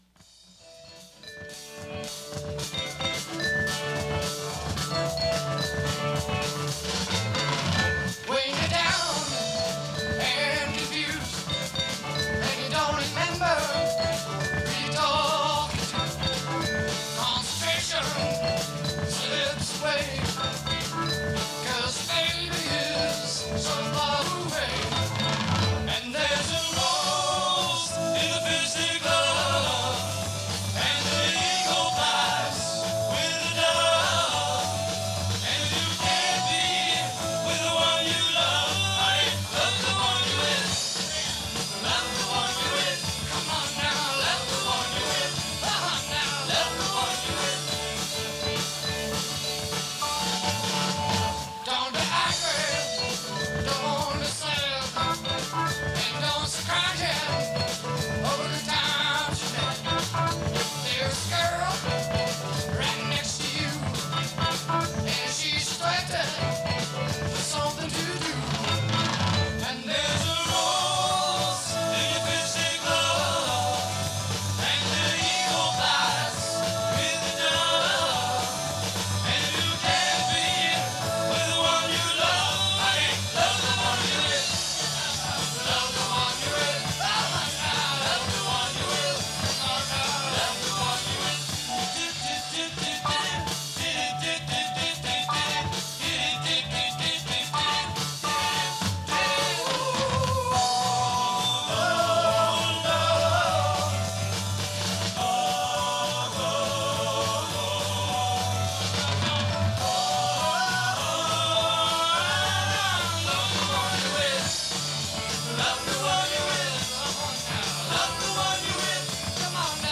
Category Rock/Jazz/Pop
Studio/Live Live
vibes and percussion
guitar and vocals
drums and percussion
bass and vocals
keyboards